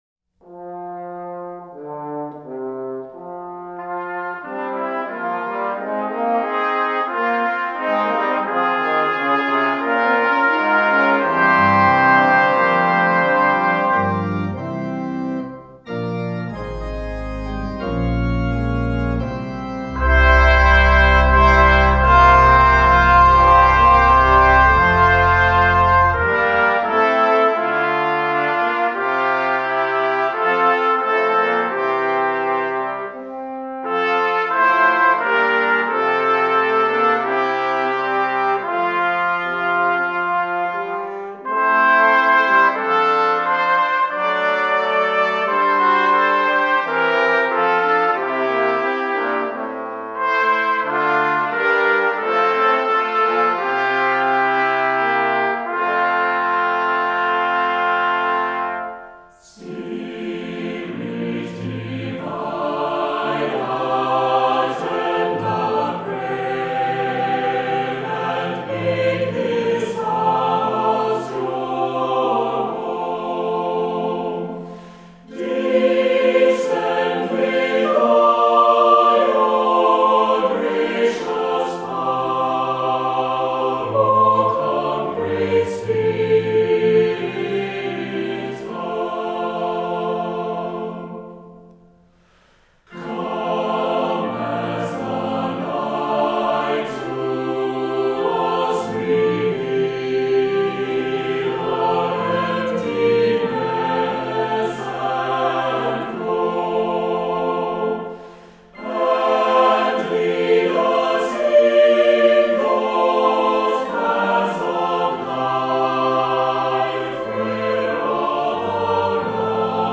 Voicing: "SATB, Assembly"